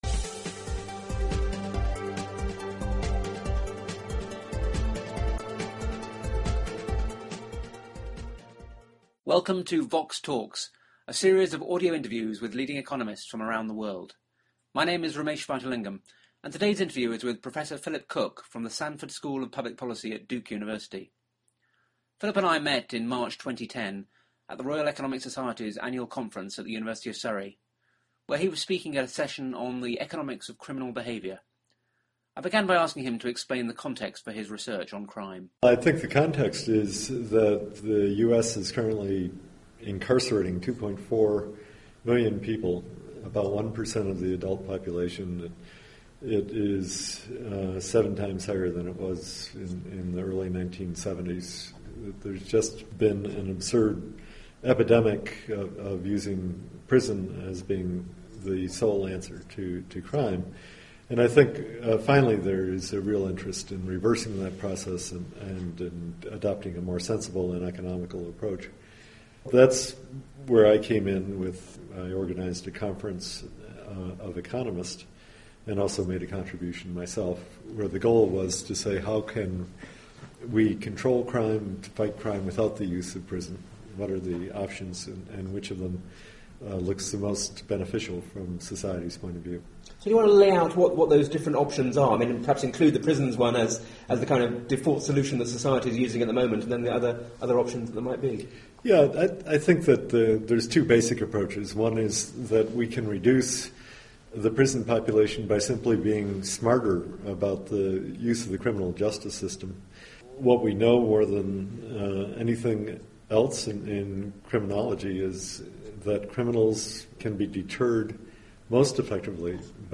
They discuss his own research on private contributions to public order, as well as the potential impact on crime of higher taxes on alcohol, 'coerced abstinence' for convicted criminals, and social policies that may deter people from becoming criminals. The interview was recorded at the Royal Economic Society's annual conference at the University of Surrey in March 2010.